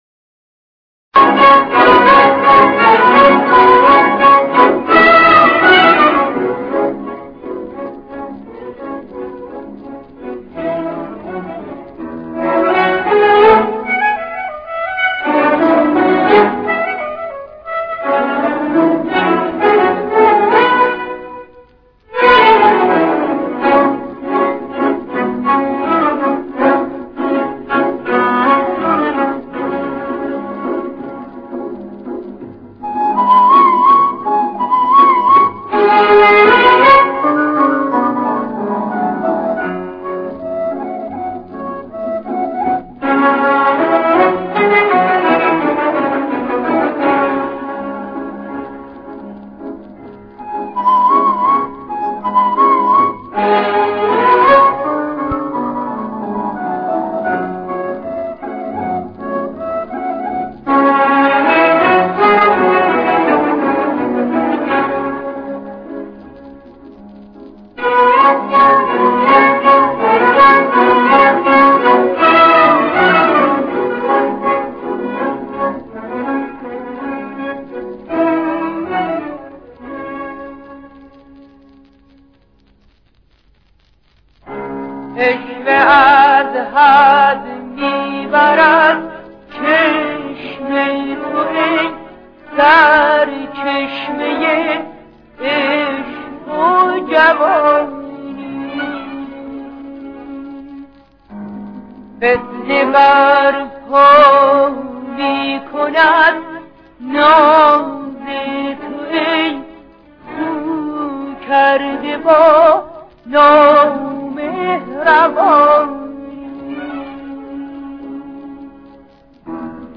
در مقام: شور